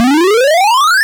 FlagSlide.wav